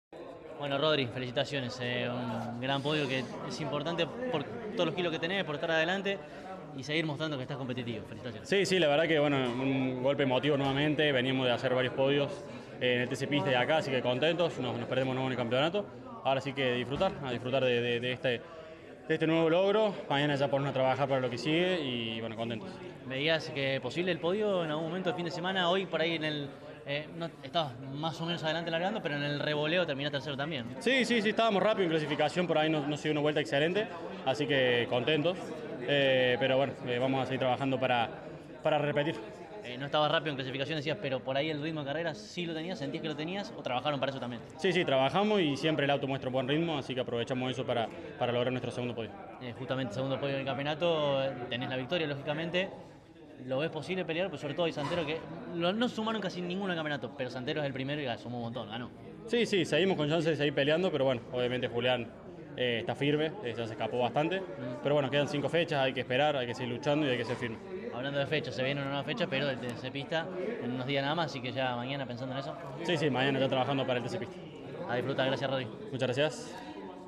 Los más encumbrados de la séptima final del año de la Clase 3 del TN, disputada en la provincia de Santiago del Estero, pasaron por los micrófonos de CÓRDOBA COMPETICIÓN.